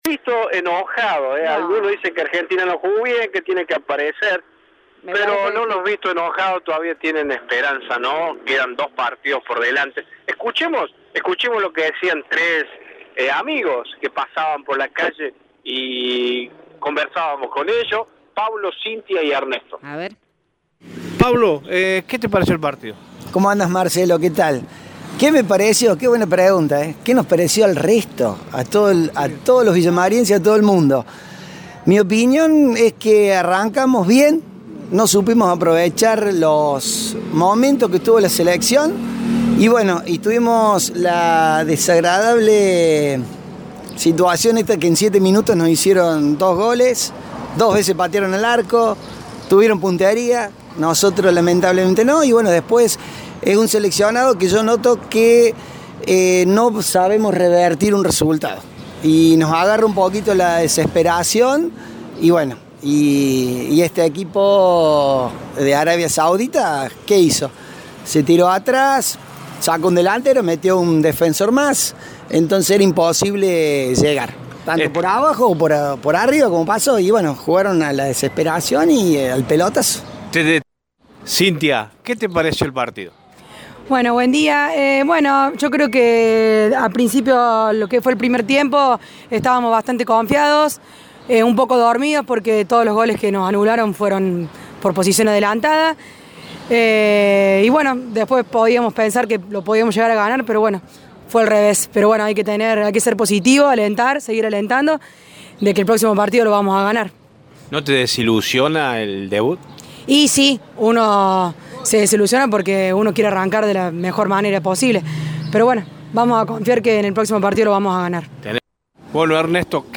El debut argentino en el mundial de Qatar fue con sabor amargo. Luego de la derrota la ciudad comenzó a movilizarse y algunos vecinos comentaron sus sensaciones postpartido.